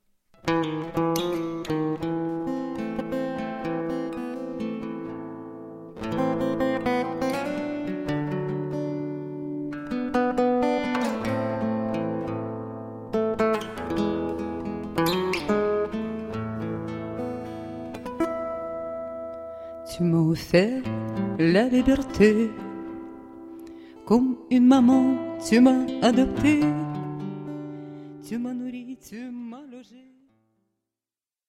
Nouvelle séance d'enregistrement